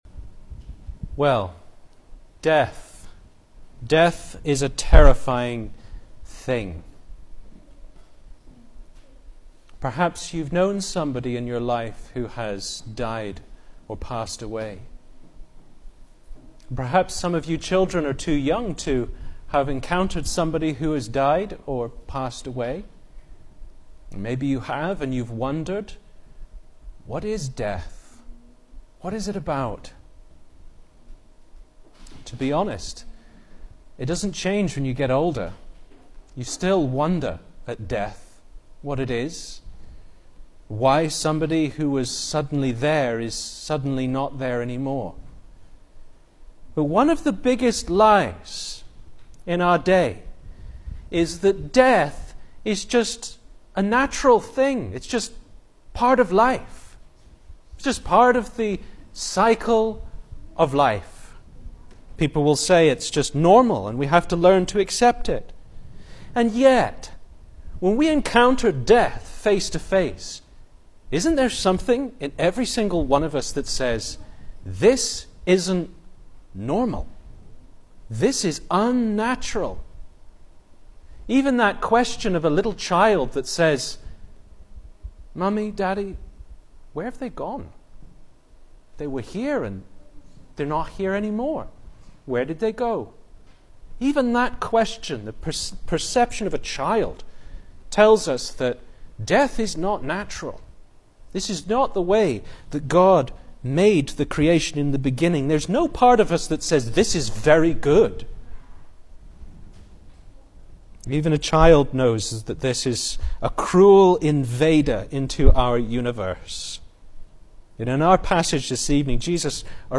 Service Type: Sunday Morning
Single Sermons